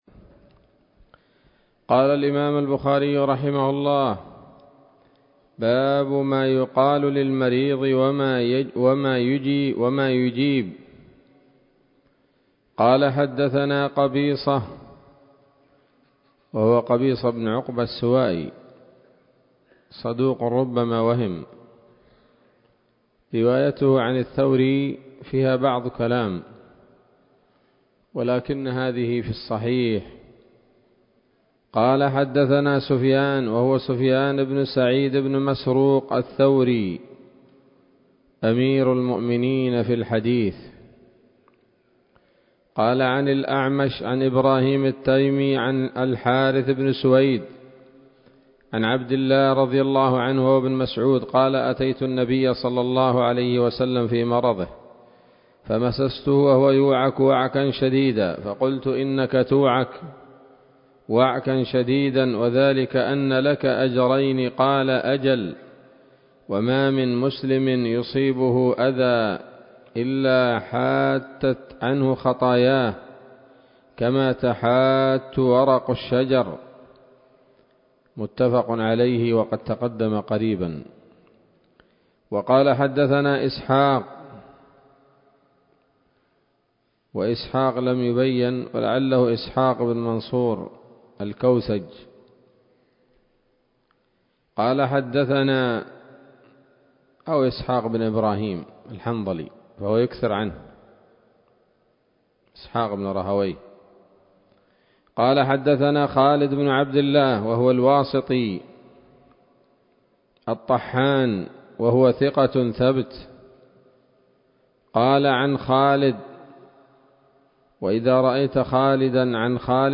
الدرس الحادي عشر من كتاب المرضى من صحيح الإمام البخاري